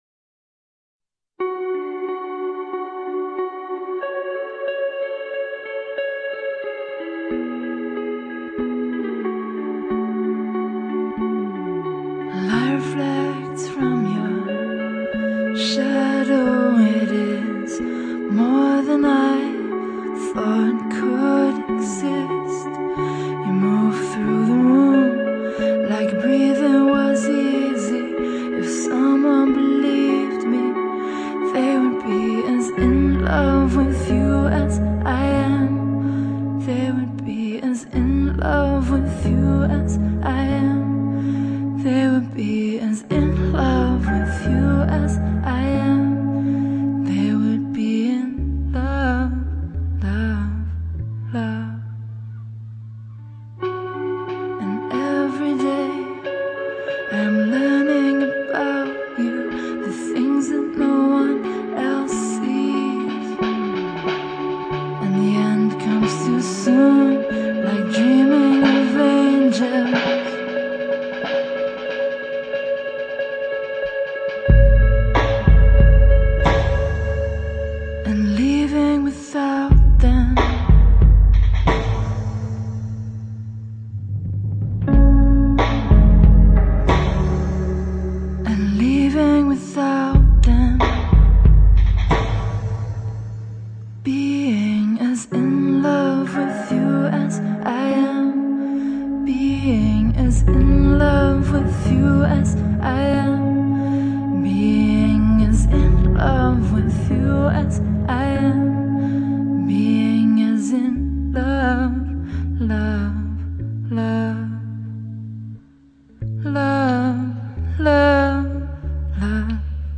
smoldering slow jam